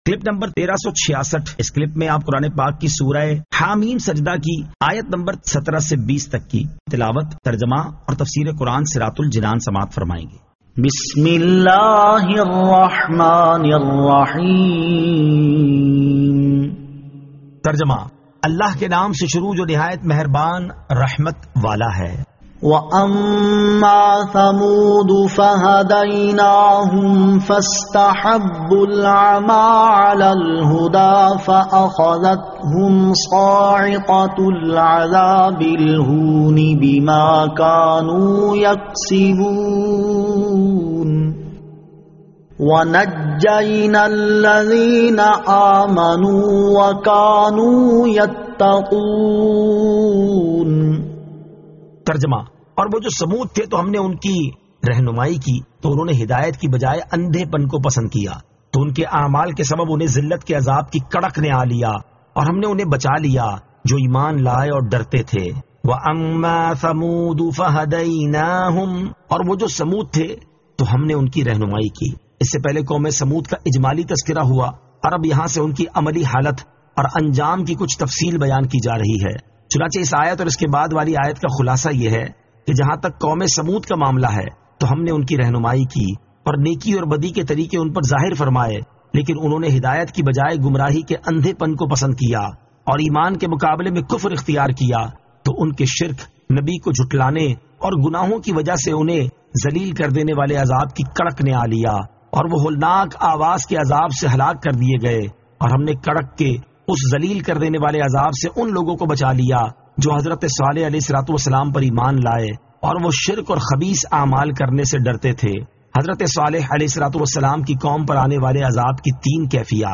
Surah Ha-Meem As-Sajdah 17 To 20 Tilawat , Tarjama , Tafseer